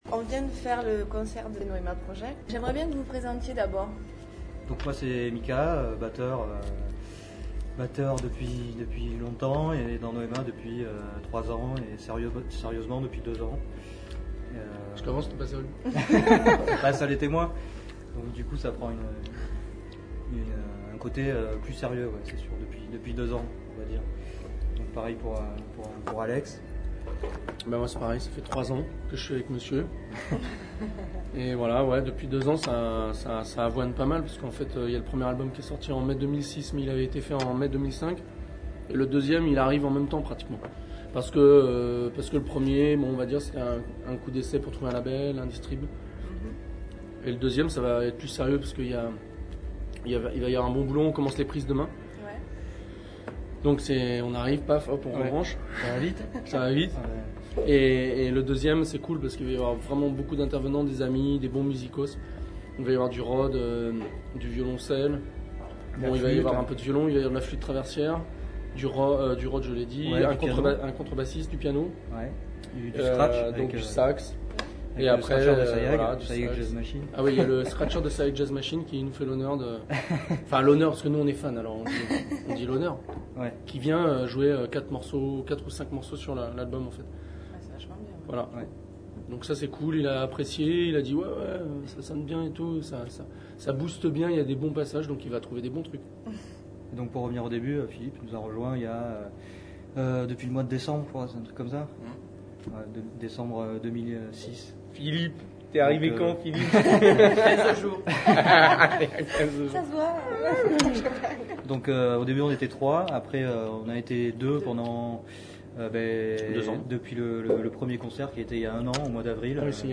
Interview de Noema project
Interview réalisée le 27/04/2007 aprés leur première partie d’Eric Truffaz à la salle des fêtes de Ramoville